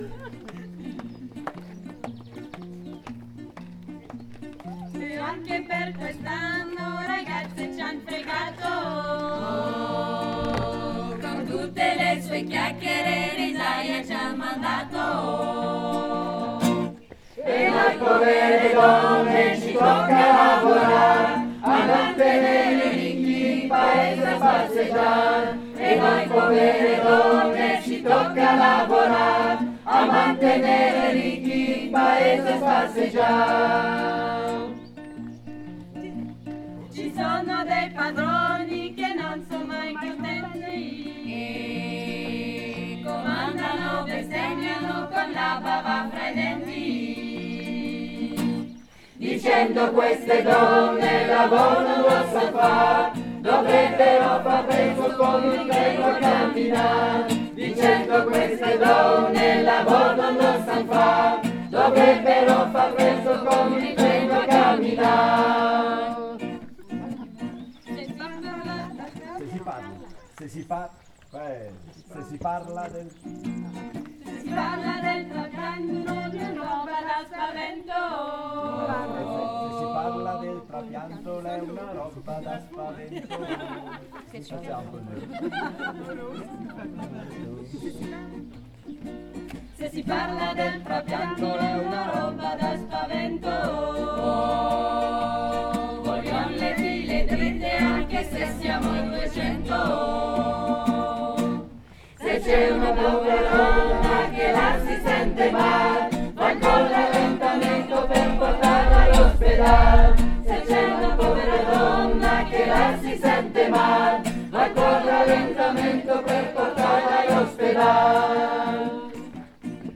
E_anche_per_quest_anno_CHORALE.mp3